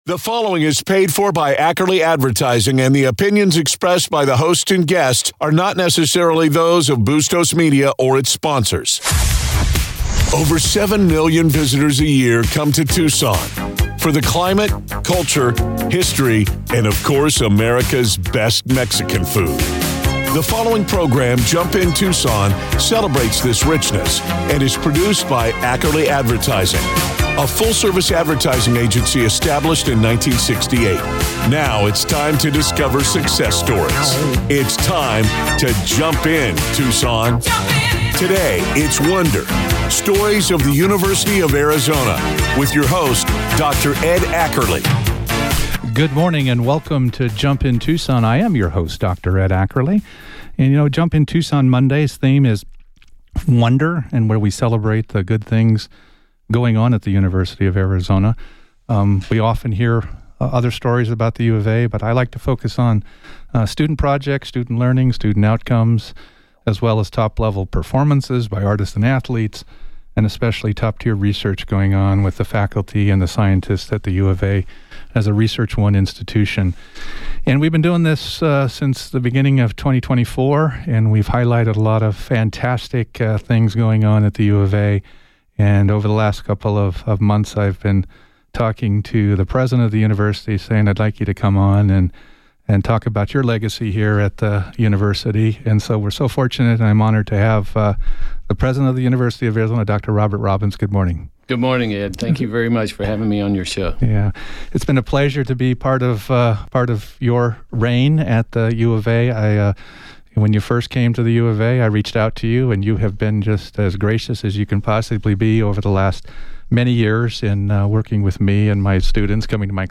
Based on the 9/23/24 Jump In Tucson Show on KVOI-1030AM .